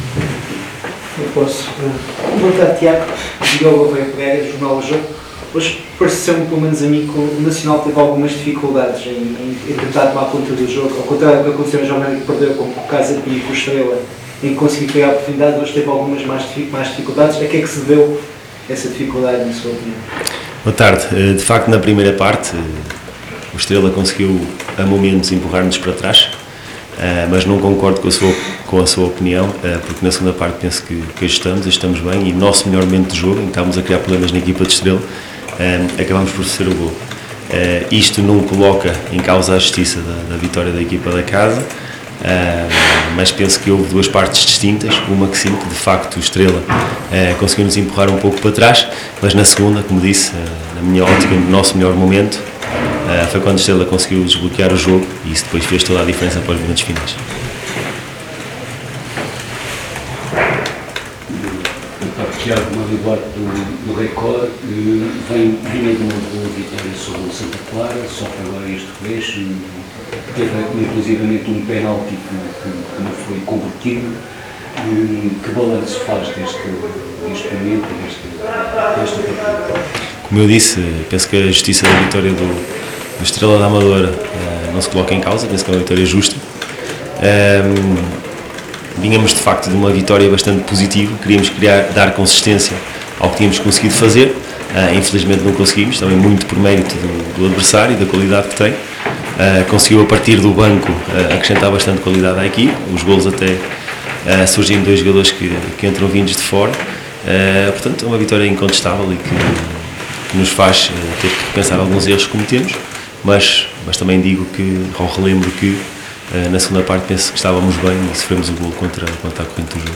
Na conferência realizada no final do encontro com o Estrela da Amadora